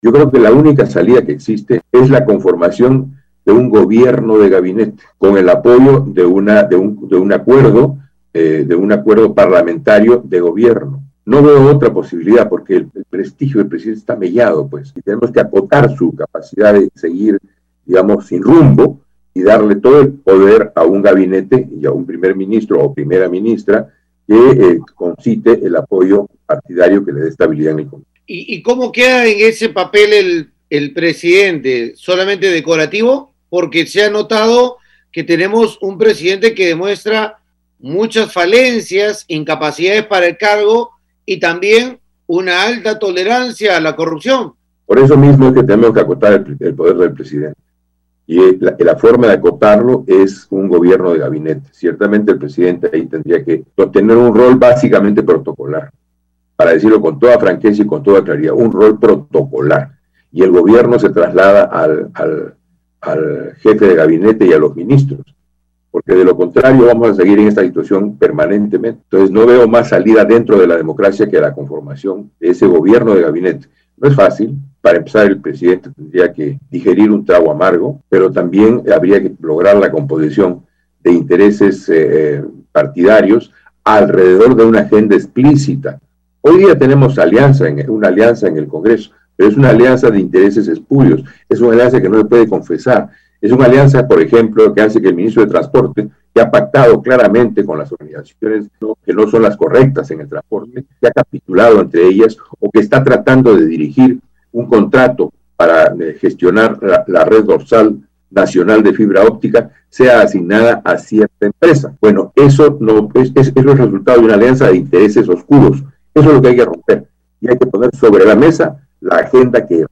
Jorge Nieto, exministro de defensa
Siendo así, afirmó vía Radio Uno que «la única salida que existe es la conformación de un Gobierno de Gabinete con el apoyo de un acuerdo parlamentario de Gobierno».